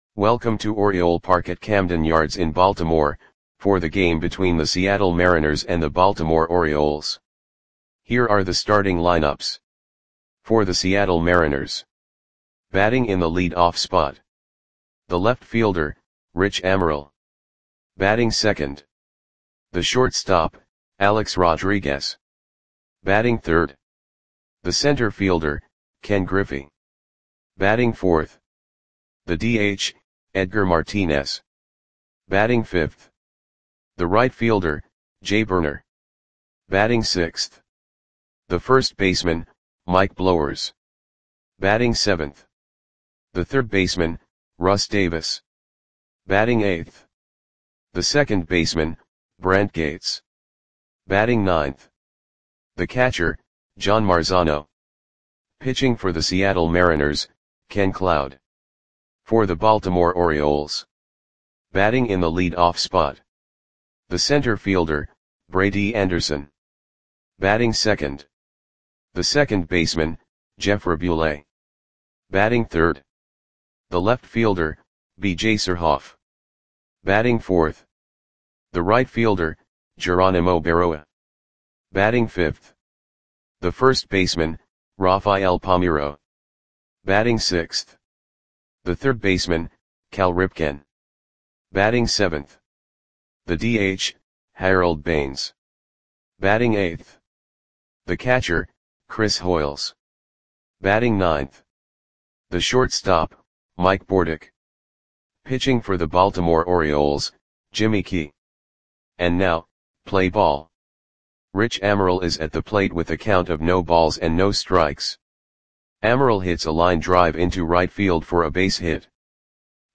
Audio Play-by-Play for Baltimore Orioles on August 15, 1997
Click the button below to listen to the audio play-by-play.